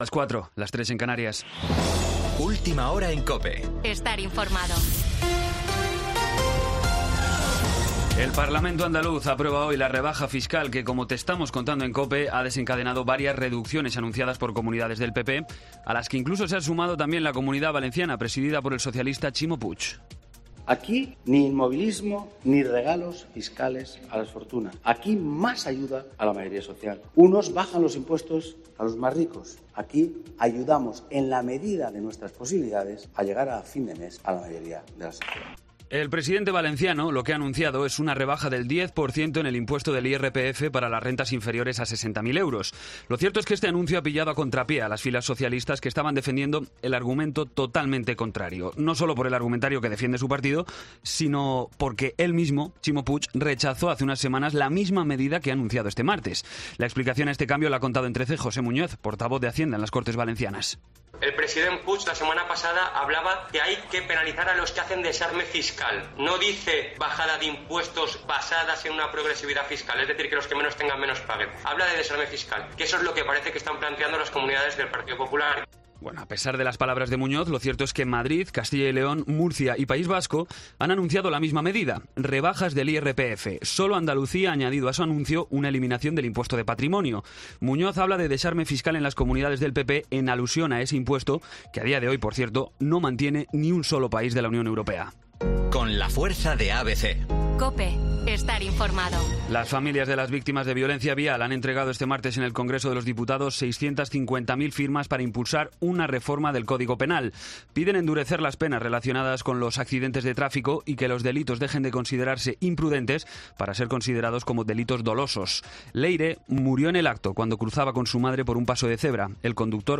Boletín de noticias COPE del 28 de septiembre a las 04:00 hora
AUDIO: Actualización de noticias Herrera en COPE